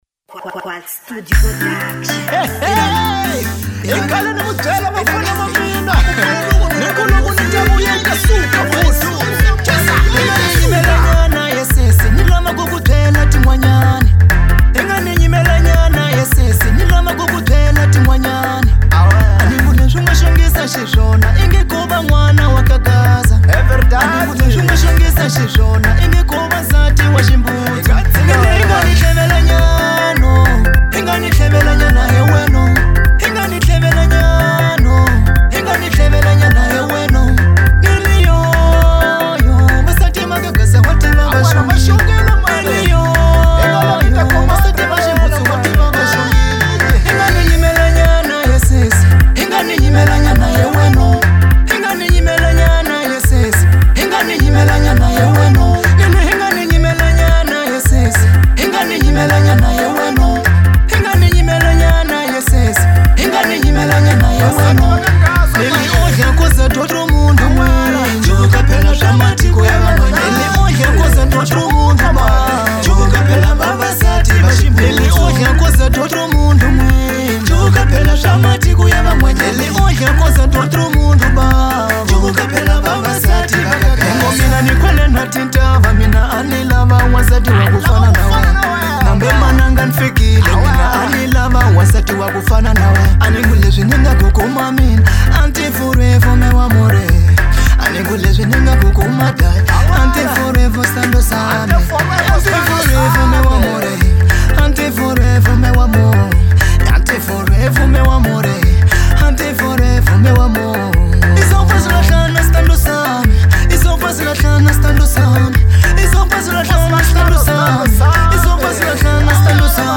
| Afro Bongo